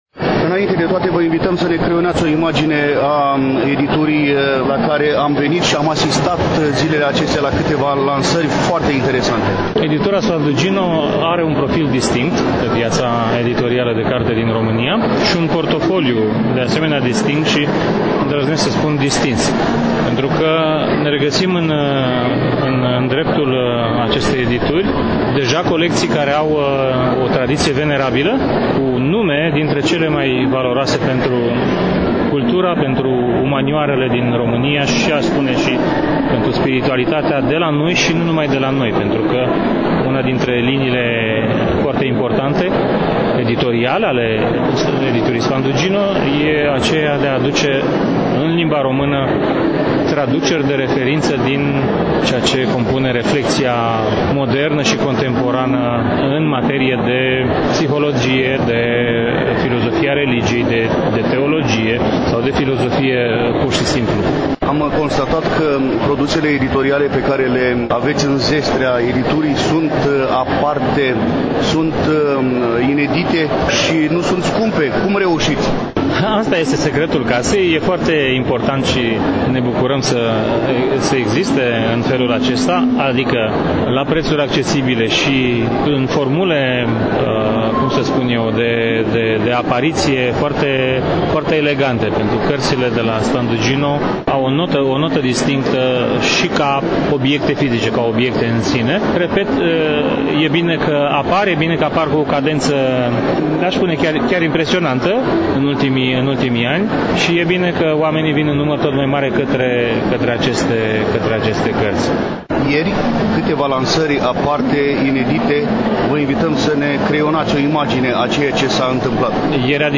Astăzi, relatăm de la Târgul de Carte Gaudeamus Radio România, ediția a XXIX-a, 7 – 11 decembrie 2022, Pavilionul B2 al Complexului Expoziţional Romexpo, București.